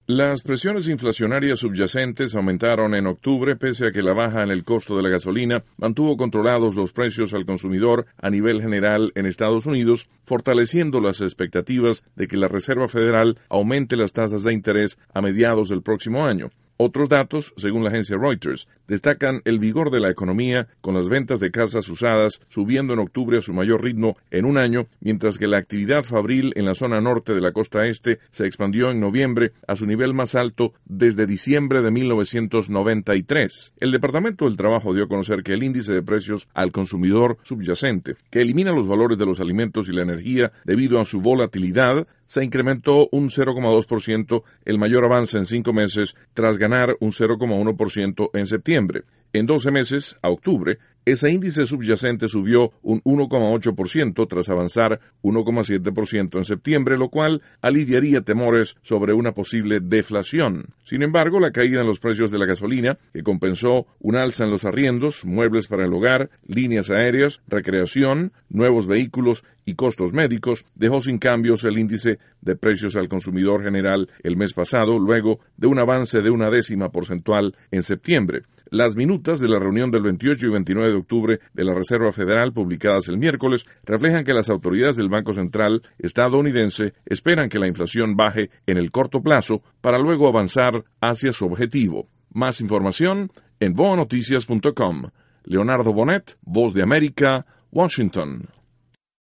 desde la Voz de América, en Washington, con los detalles.